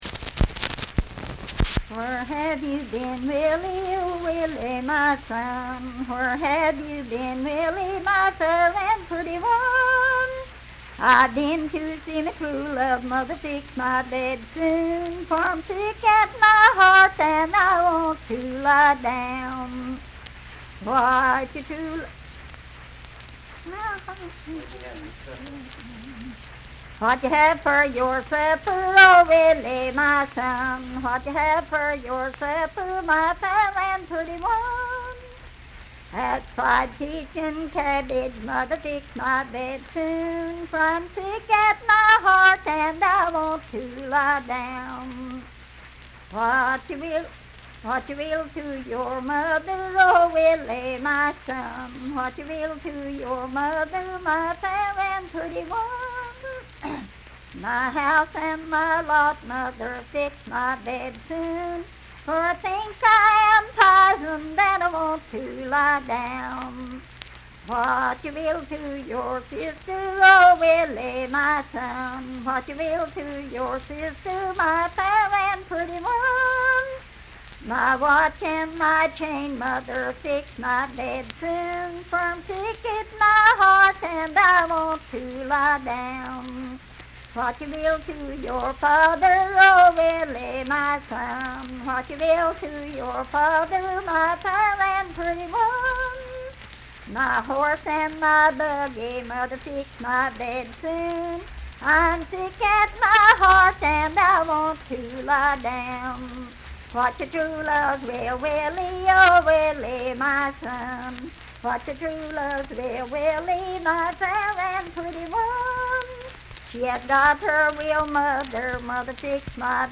Unaccompanied vocal music
Verse-refrain 6(4w/r).
Voice (sung)
Logan County (W. Va.)